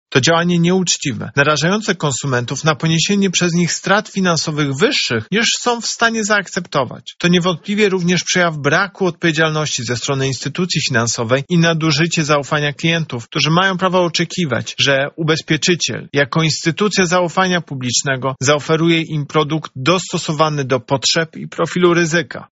• mówi prezes UOKiK Tomasz Chróstny.